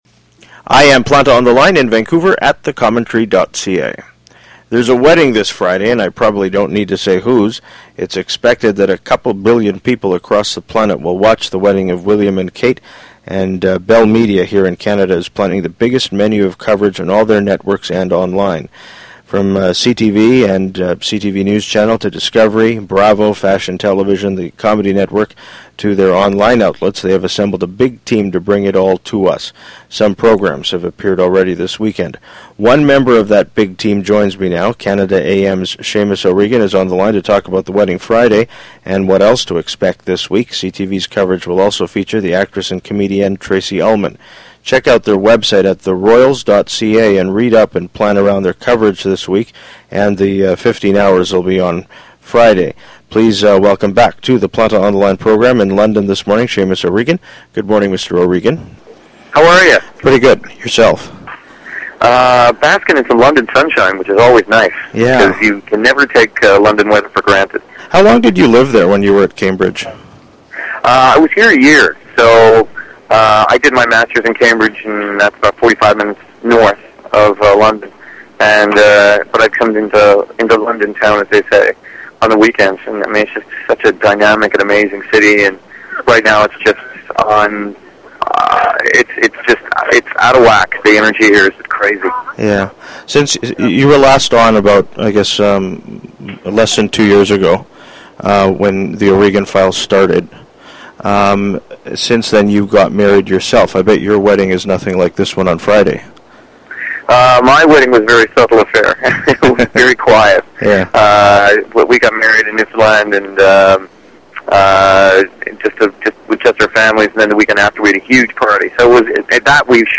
Canada AM’s Seamus O’Regan calls in from London, England to talk about CTV’s coverage of the Royal Wedding, covering William and Kate, and more